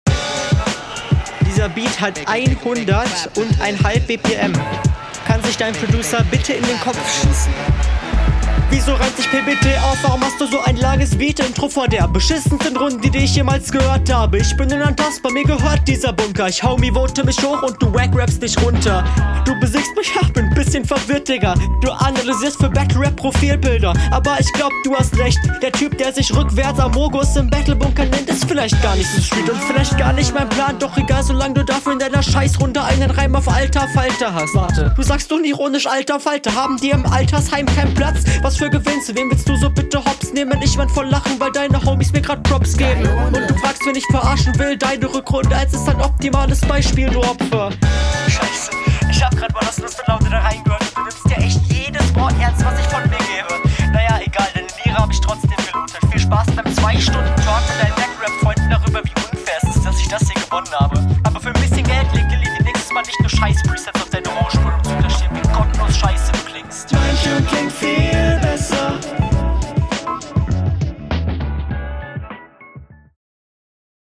Beat liegt der leider net so gut